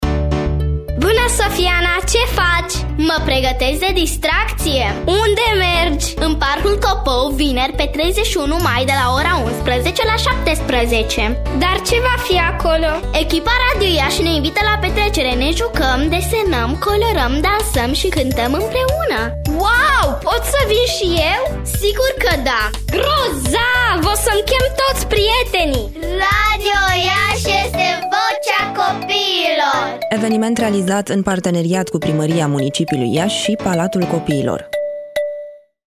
Voce copii
Compoziție muzicală & Producție audio
SPOT_VOCEA-COPIILOR-RI.mp3